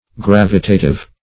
\Gravi*ta*tive\